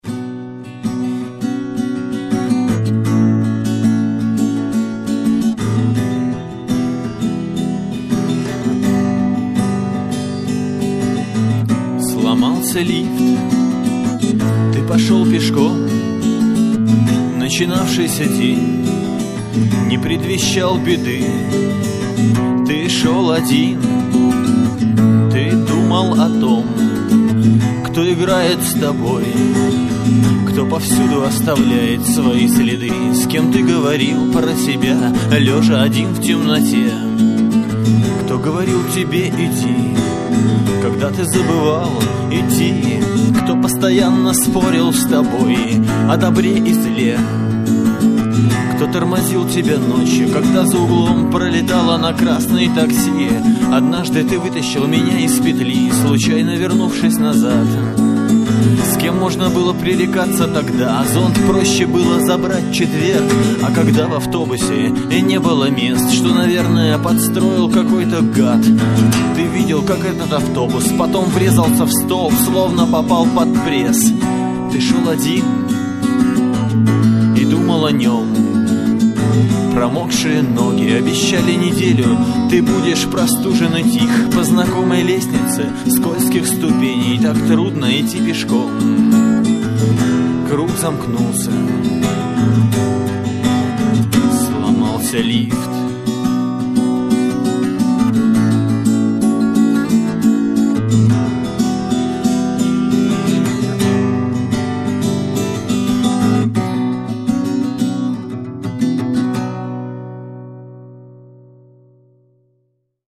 112 kbps, stereo, studio sound. audio live